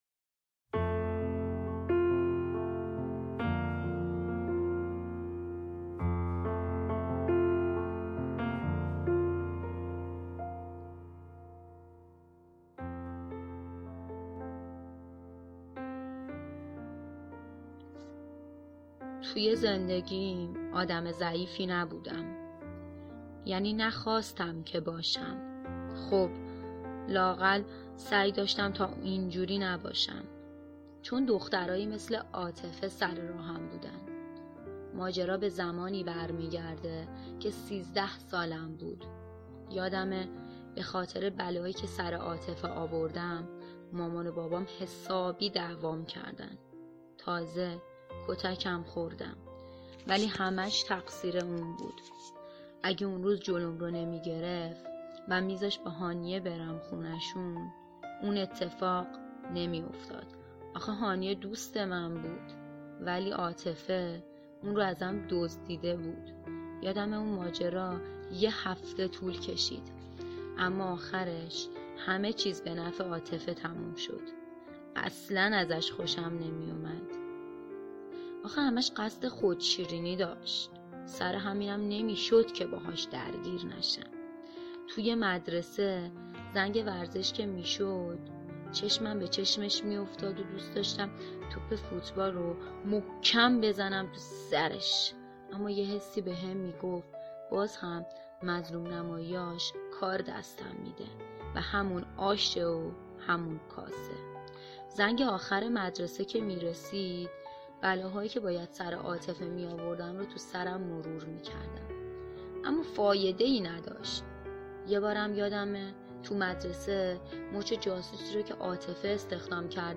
قطعه موسیقی اثر جیم بریکمن